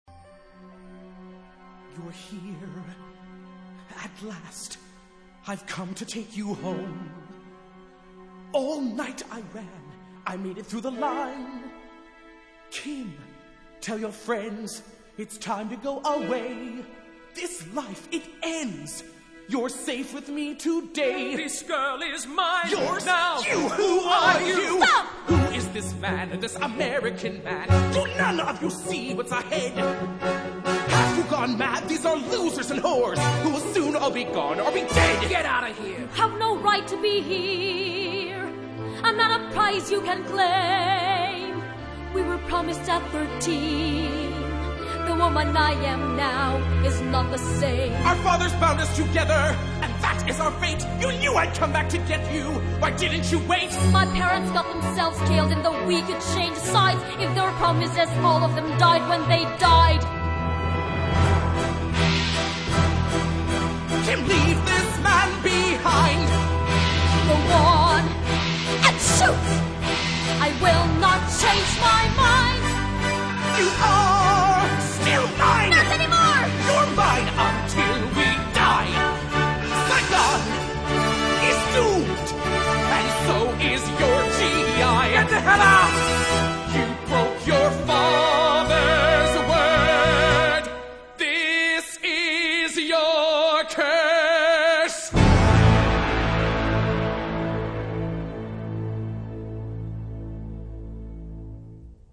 這首歌可以聽得出一點悲慘世界的影子